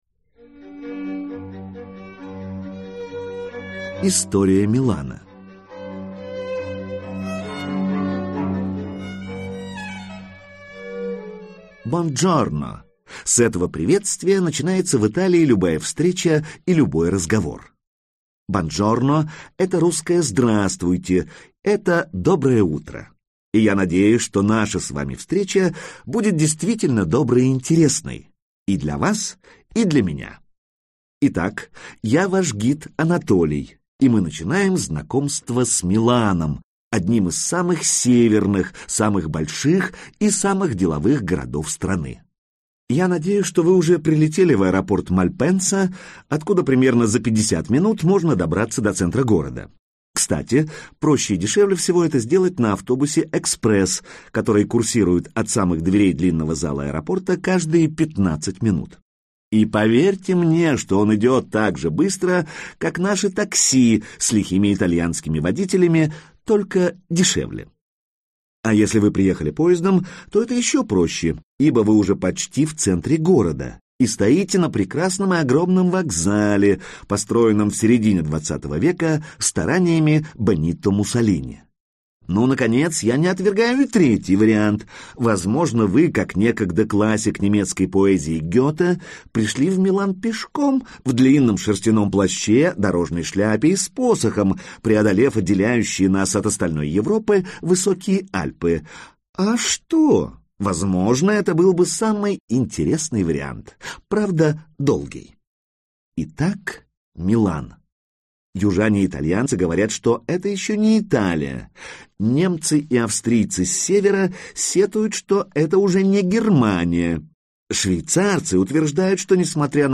Аудиокнига Милан. Путеводитель | Библиотека аудиокниг